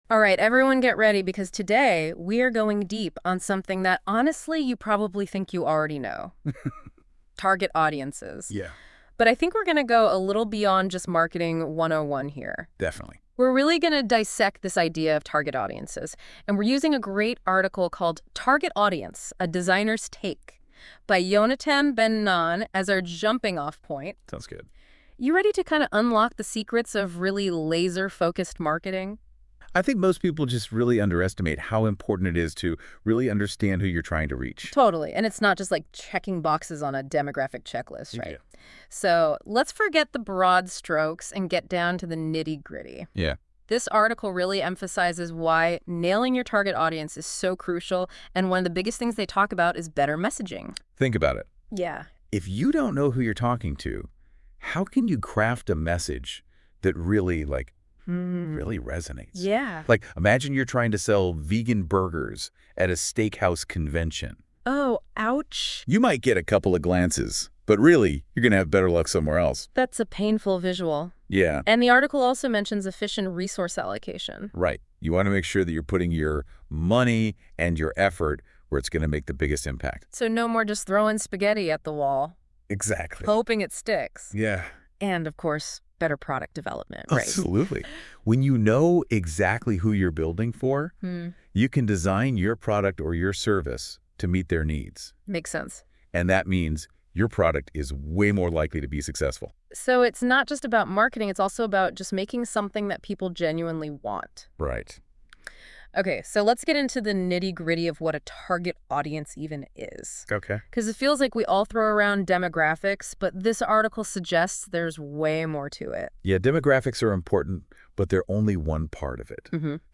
This NotebookLM show covers the article with an energetic, over-the-top, nauseating North American speech.